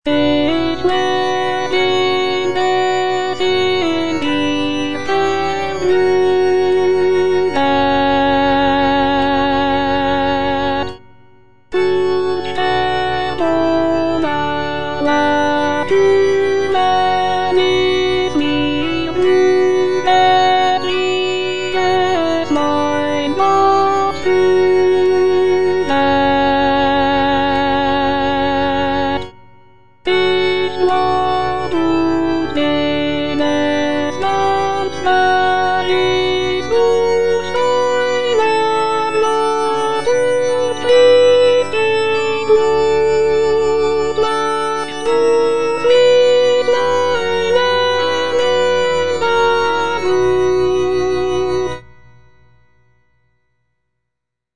Choralplayer playing Cantata
The work features a joyful and optimistic tone, with the solo soprano expressing gratitude for the blessings in her life.